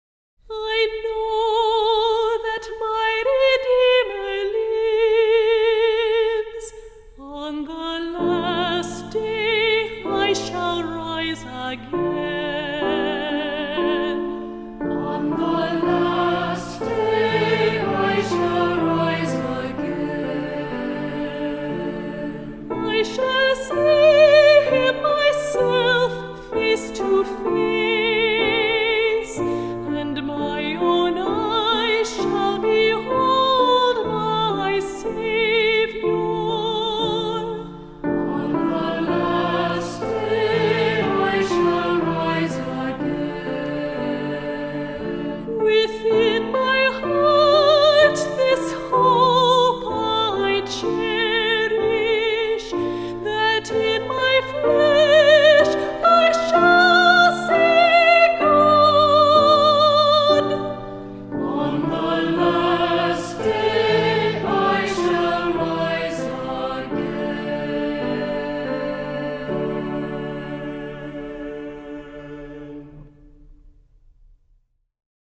Voicing: Cantor,SATB,Assembly